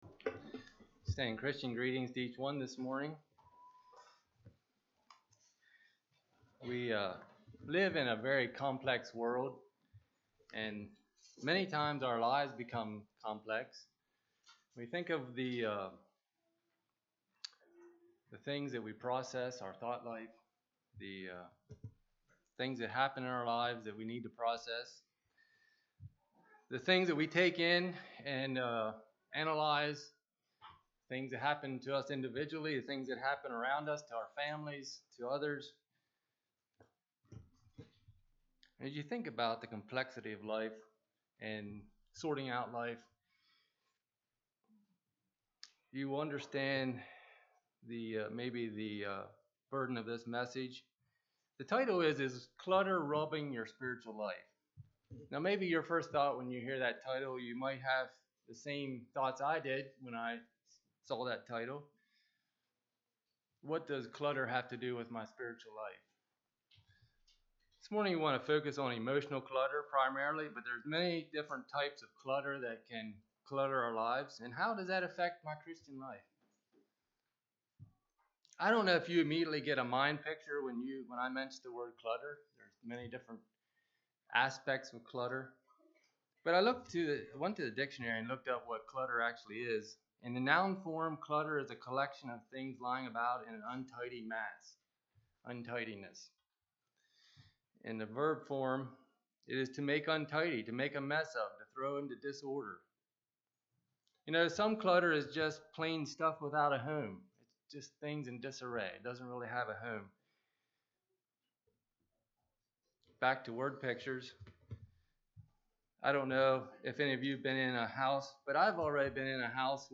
Congregation: Elm Street
Sermon